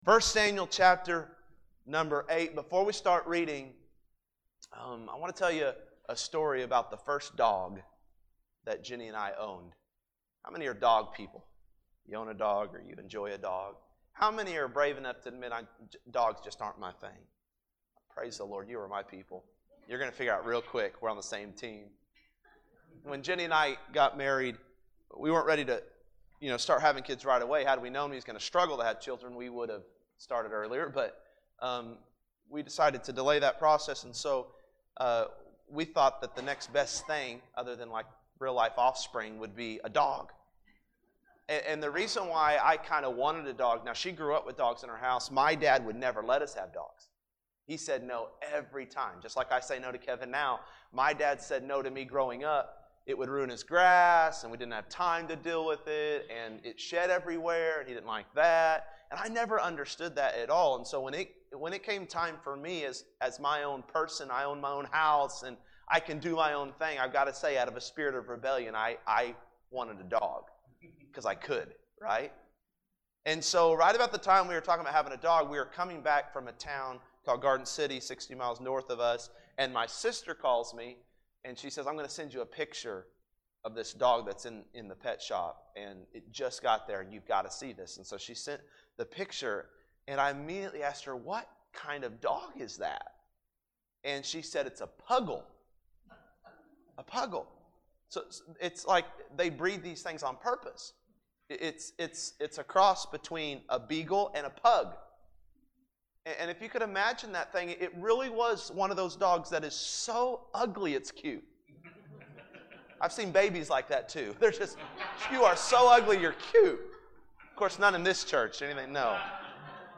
1Samuel 8:1-22 – Fall Revival Monday PM